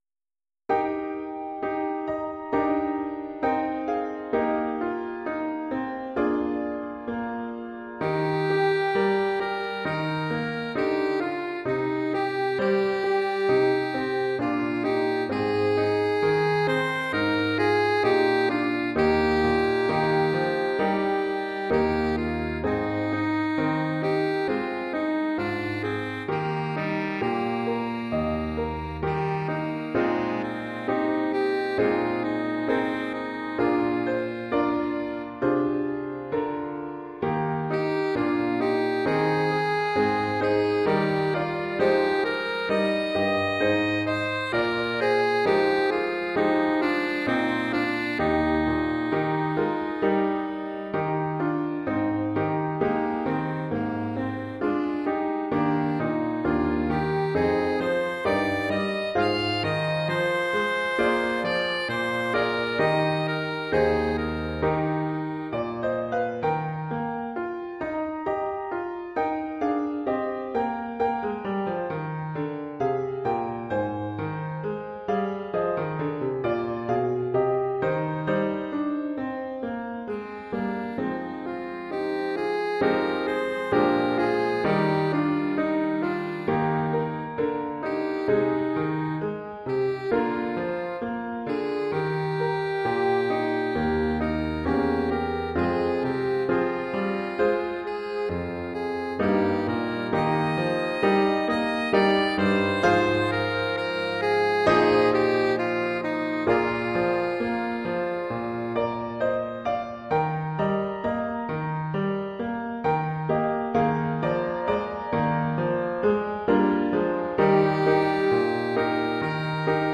oeuvre pour saxophone alto et piano.
Niveau : débutant (1er cycle).